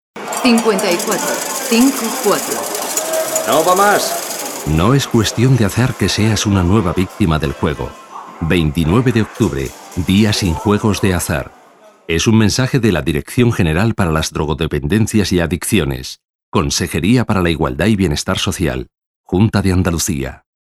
1. Emisión de cuñas de radio: